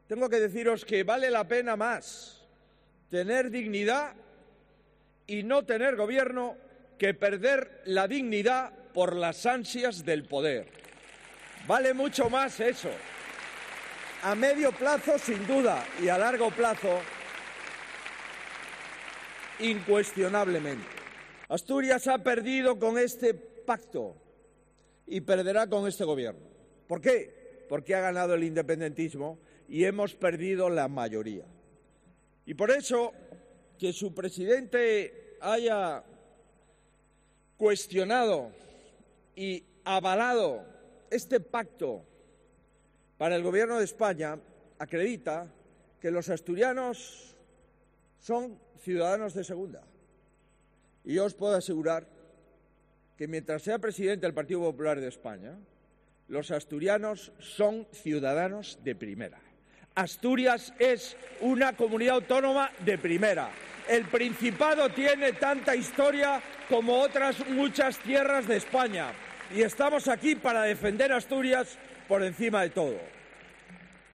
Declaraciones de Núñez Feijóo en Oviedo